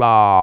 KlattExample.wav